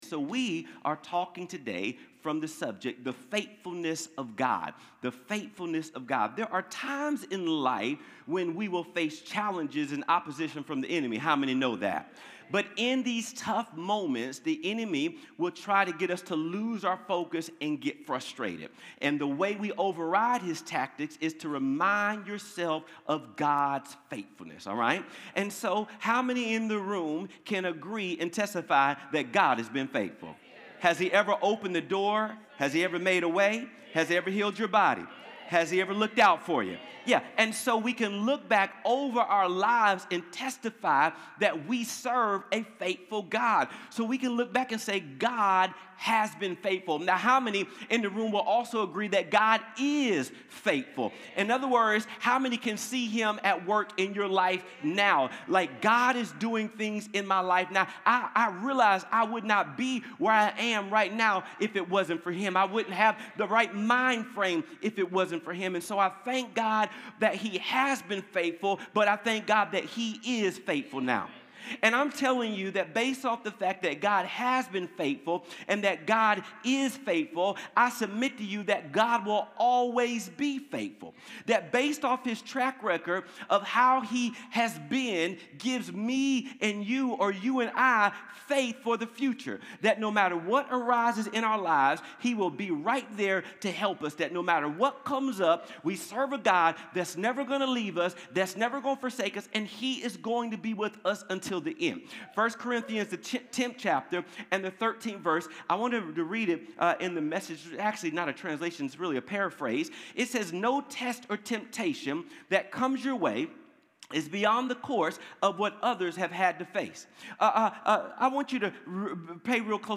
Connect Groups Events Watch Church Online Sermons Give The Faithfulness of God June 22, 2025